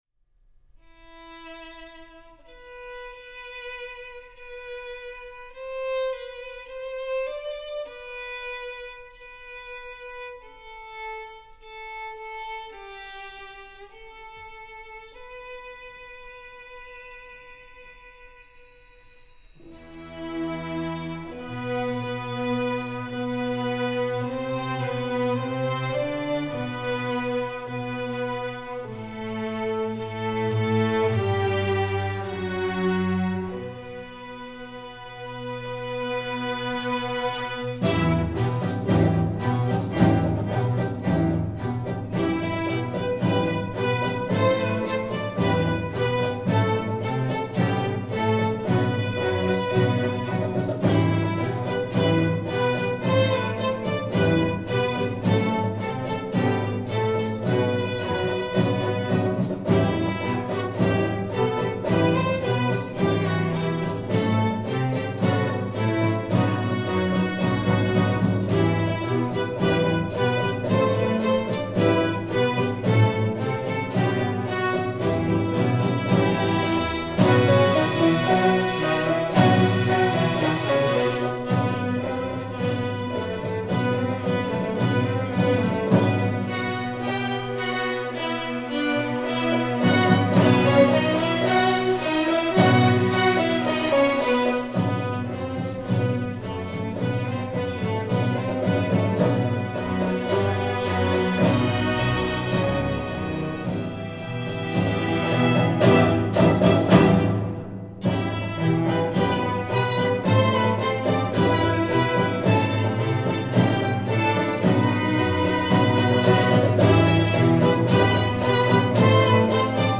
Composer: Traditional Turkish
Voicing: String Orchestra